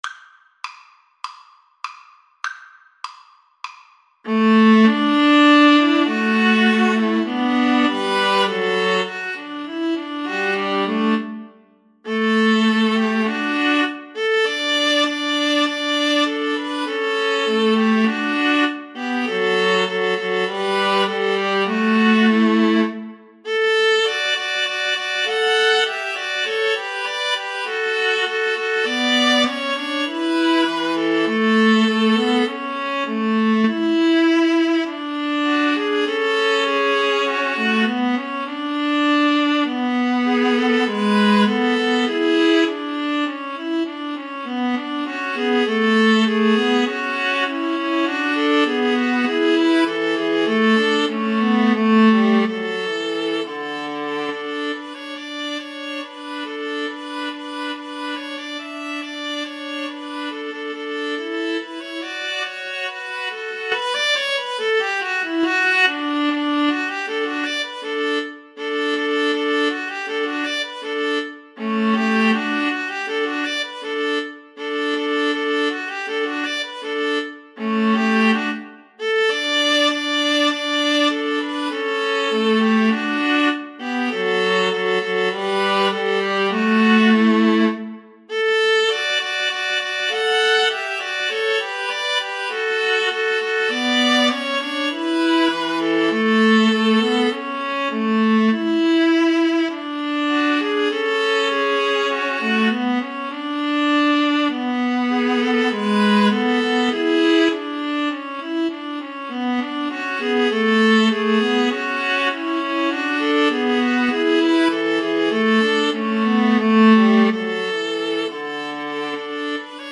Free Sheet music for Viola Trio
D major (Sounding Pitch) (View more D major Music for Viola Trio )
4/4 (View more 4/4 Music)
Moderato
Viola Trio  (View more Easy Viola Trio Music)
Classical (View more Classical Viola Trio Music)
abritishtarVLAtrio_kar2.mp3